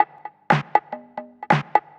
Session 14 - Percussion 03.wav